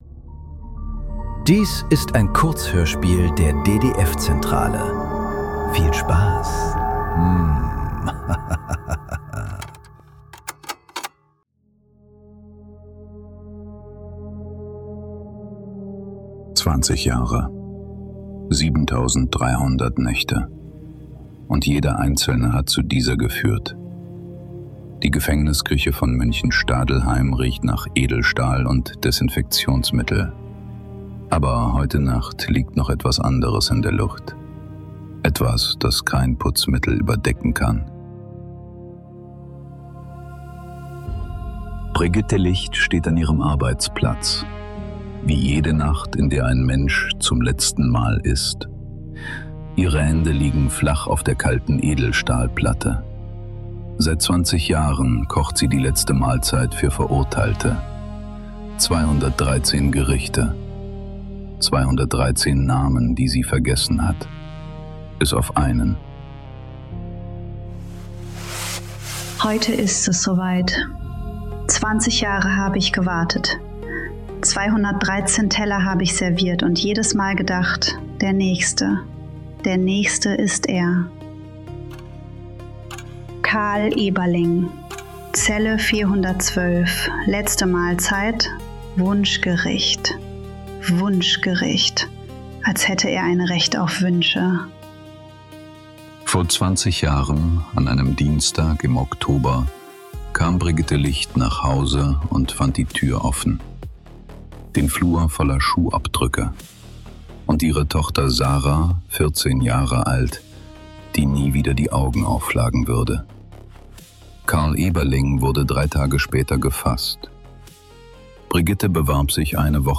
Das letzte Gericht ~ Nachklang. Kurzhörspiele. Leise.